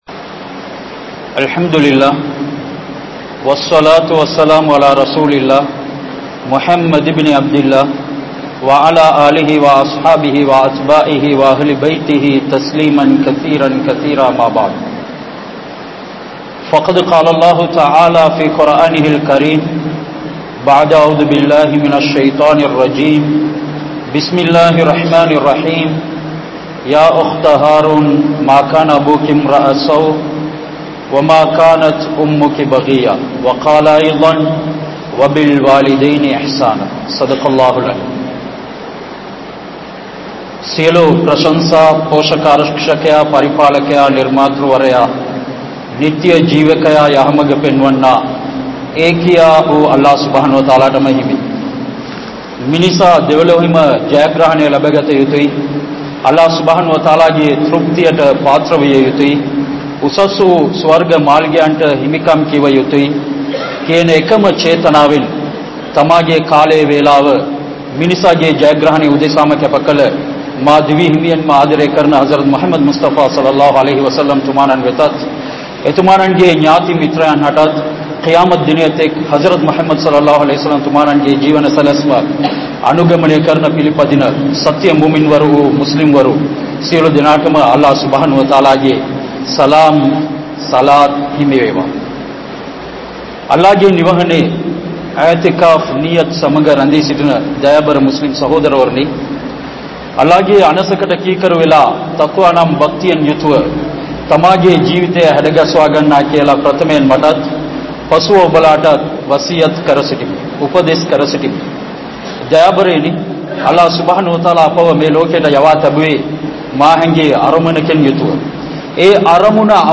Petroarhalai Maranthu Vidaatheerhal (பெற்றோர்களை மறந்து விடாதீர்கள்) | Audio Bayans | All Ceylon Muslim Youth Community | Addalaichenai
Kaluthura, Muhideen (Teru Palli) Jumua Masjith